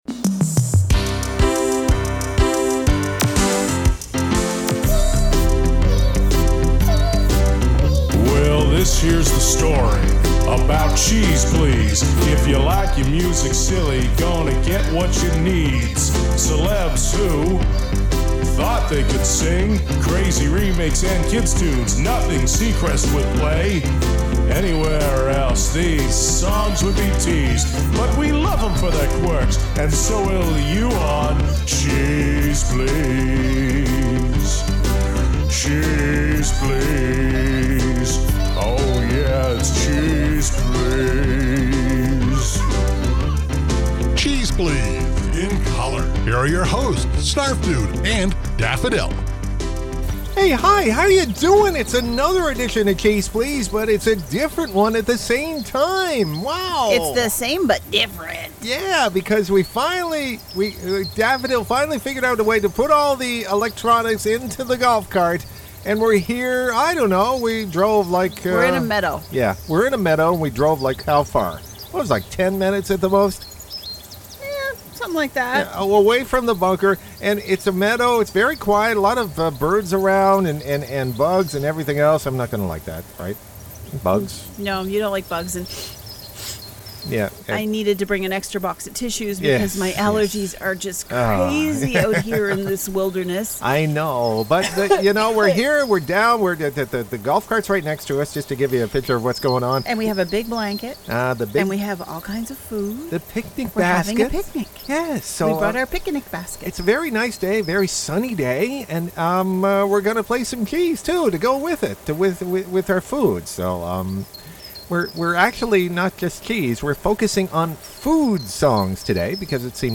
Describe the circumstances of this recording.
Our hosts finally have some prolonged time outside of the bunker as they broadcast from a picnic...